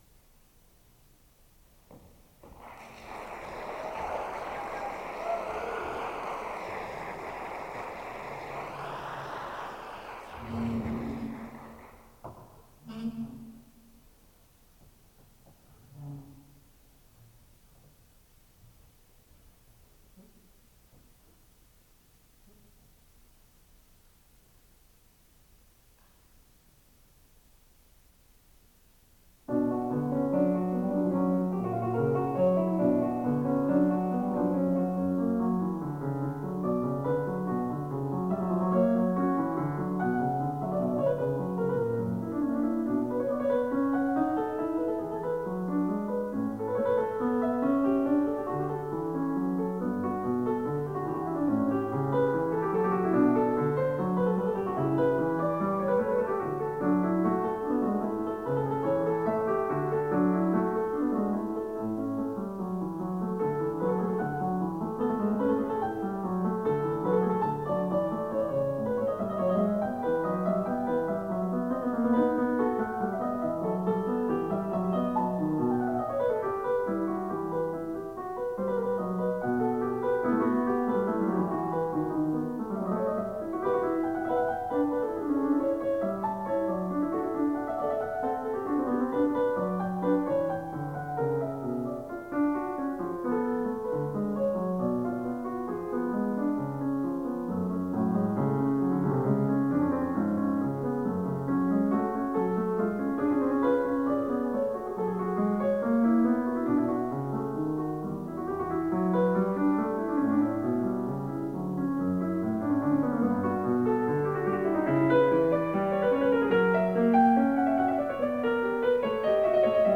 Piano SENIOR RECITAL